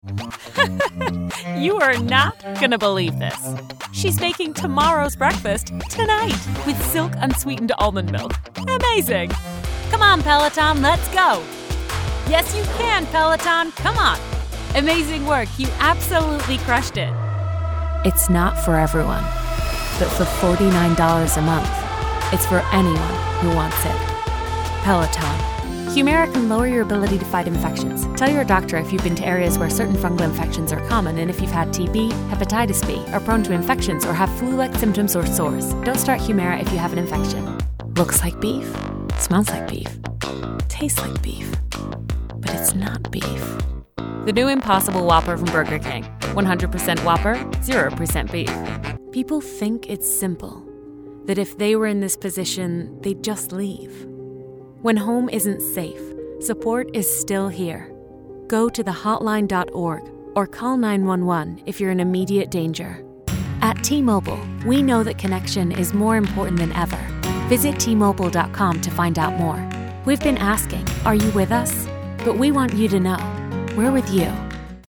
Teenager, Adult, Young Adult
Has Own Studio
standard us
commercial
friendly
smooth
warm
well spoken